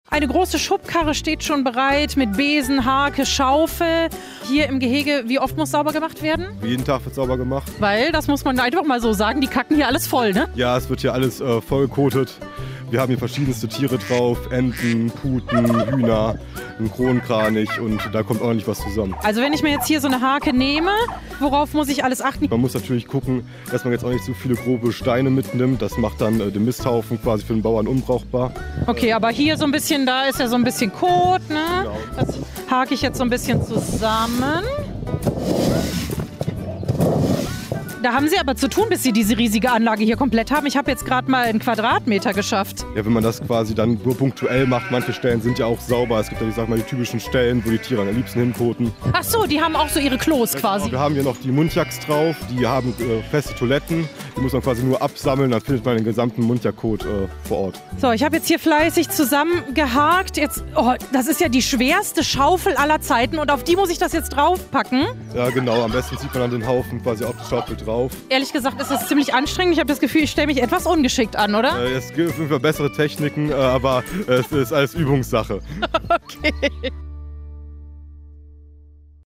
repo_fauna_1_gefluegel-gehege_harken.mp3